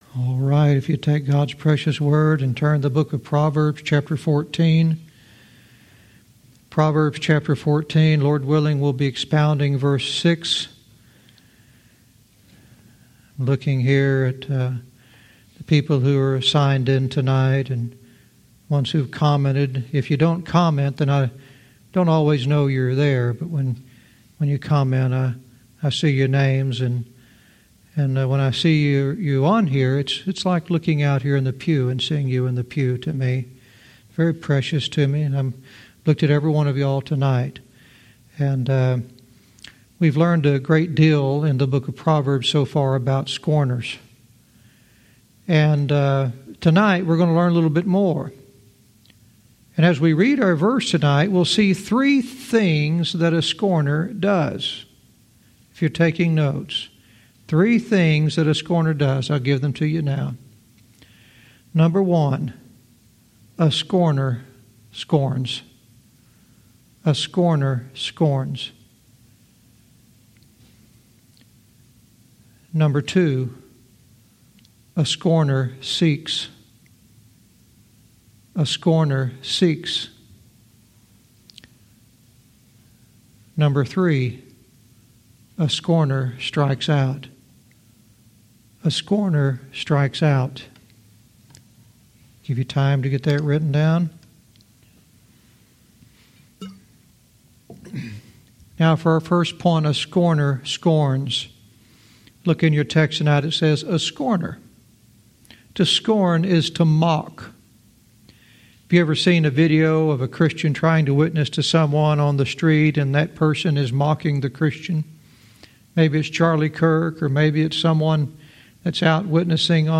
Verse by verse teaching - Proverbs 14:6 "All The Wrong Places"